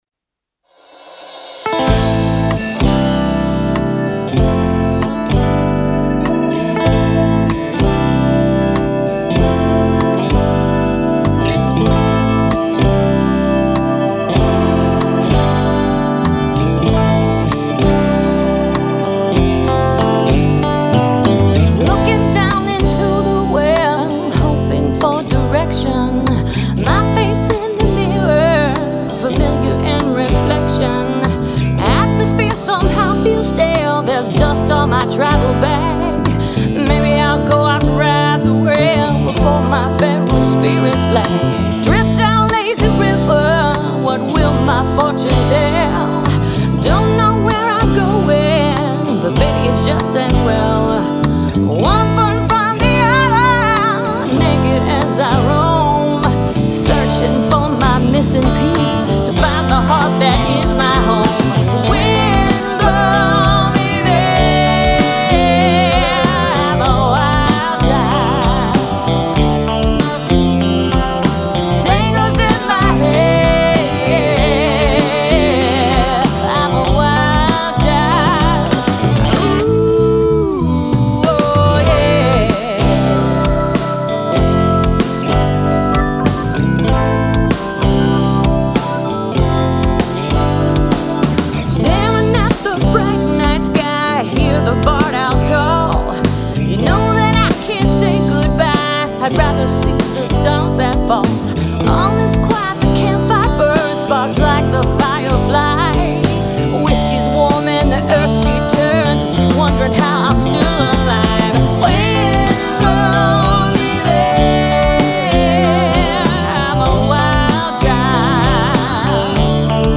Full Band